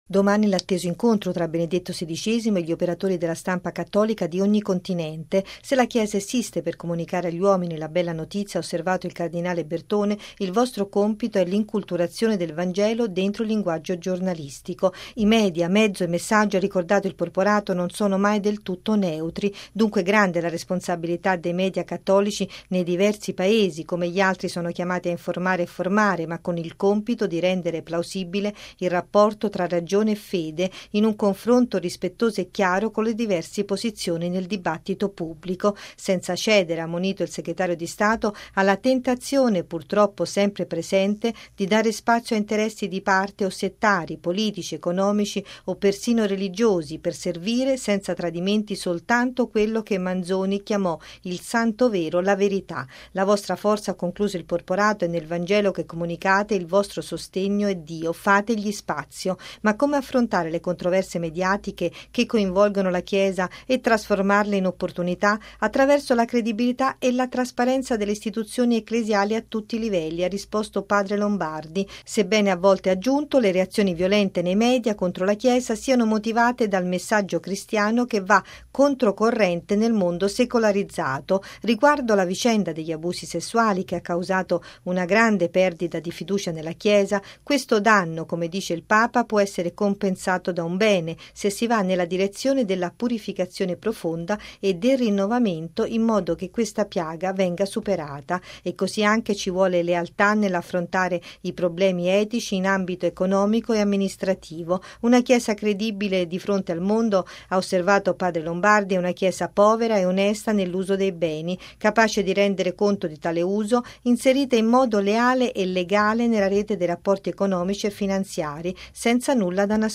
Tra gli interventi di ieri, padre Federico Lombardi, direttore della Sala Stampa vaticana, ha parlato di come affrontare le controversie mediatiche. Il servizio